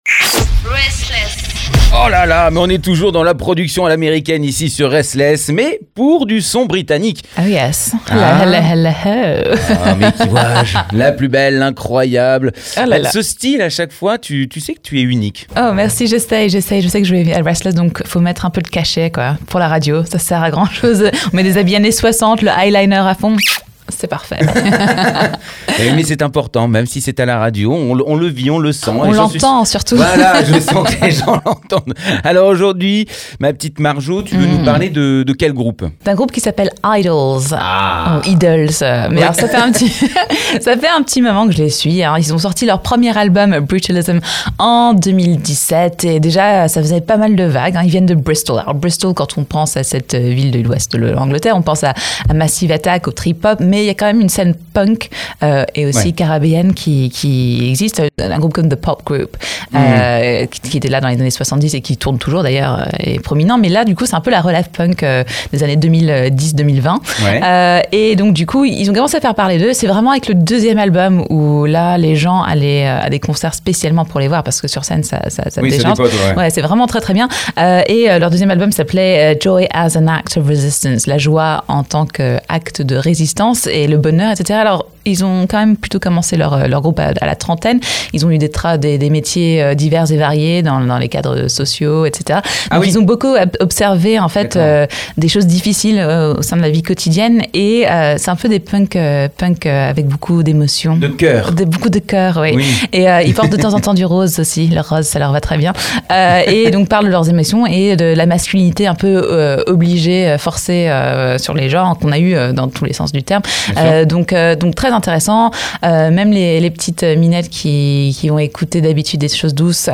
c’est tous les mardis à 19h sur RSTLSS Radio.